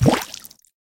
clancy_atk_hit_env_01.ogg